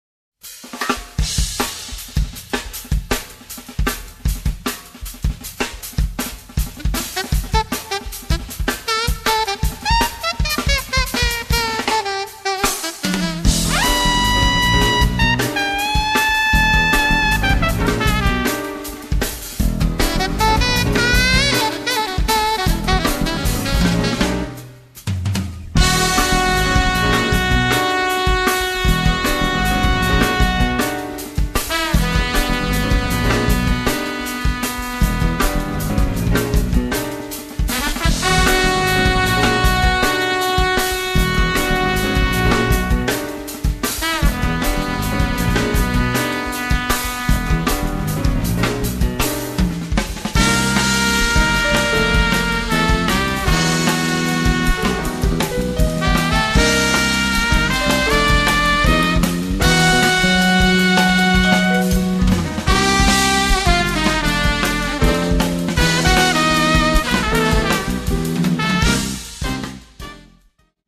tromba e flicorno
sax tenore, alto e soprano
pianoforte
basso elettrico
batteria